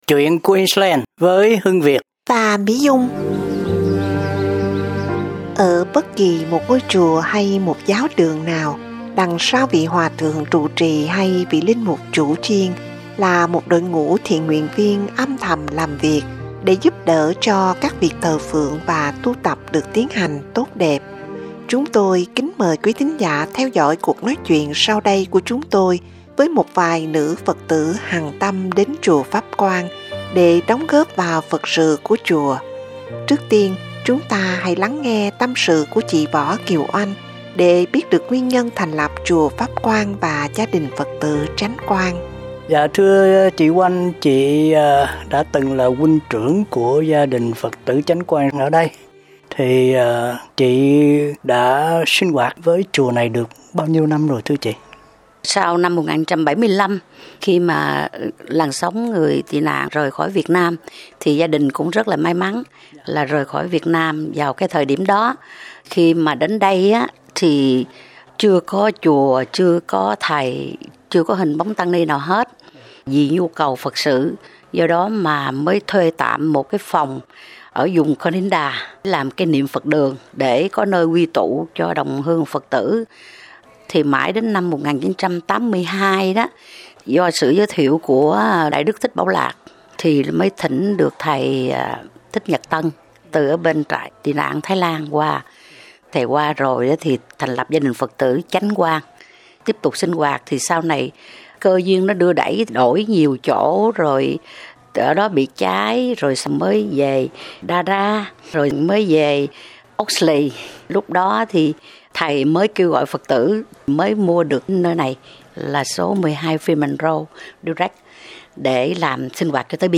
Chúng tôi kính mời quý thính giả theo dõi cuộc nói chuyện sau đây của chúng tôi với một vài nữ Phật tử hằng tâm đến chùa Pháp Quang để đóng góp vào Phật sự của chùa.